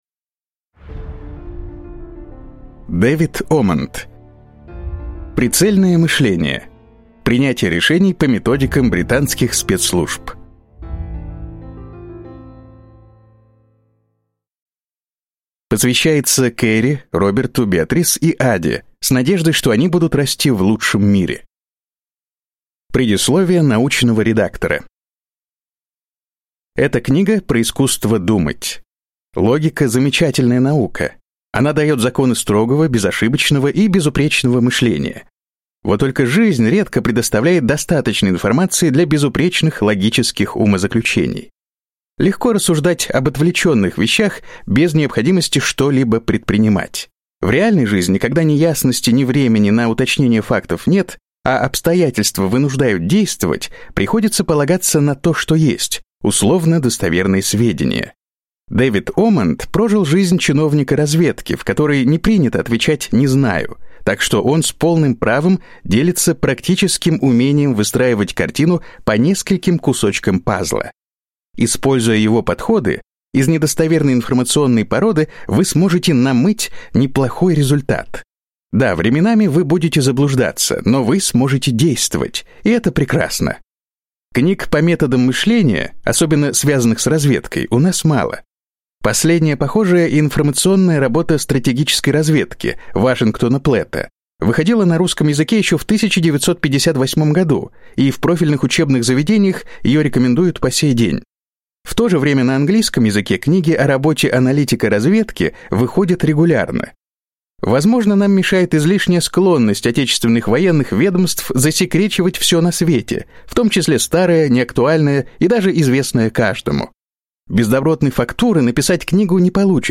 Аудиокнига Прицельное мышление. Принятие решений по методикам британских спецслужб | Библиотека аудиокниг